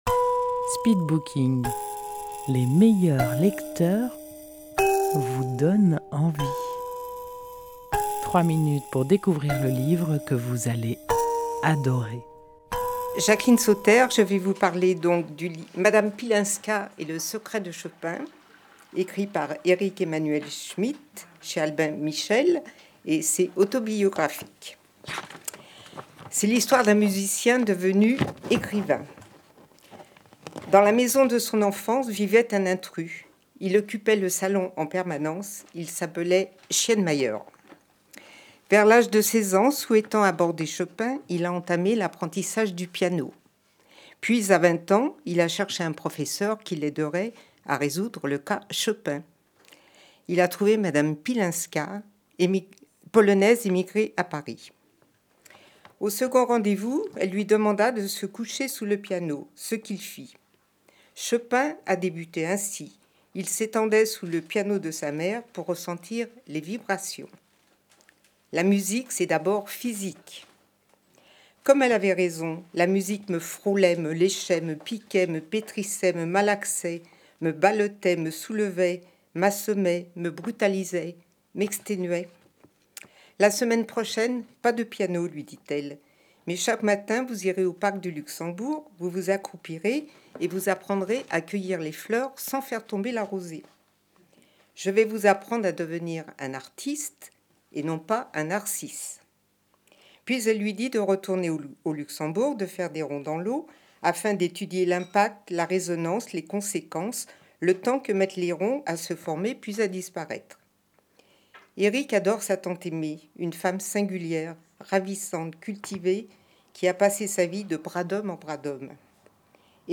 Speed booking Rendez-vous au speed booking : les meilleurs lecteurs vous font partager leur passion pour un livre en 3 minutes chrono. Enregistré en public au salon de thé Si le cœur vous en dit à Dieulefit.